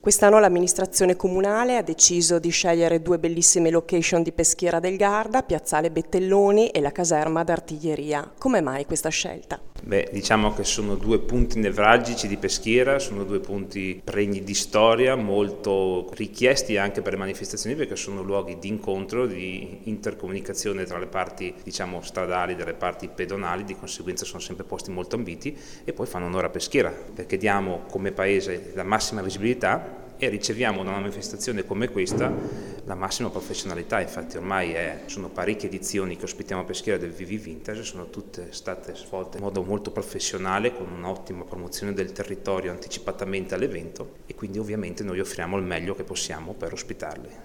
Filippo Gavazzoni, vicesindaco di Peschiera
Filippo-Gavazzoni-Vicesindaco-di-Peschiera.mp3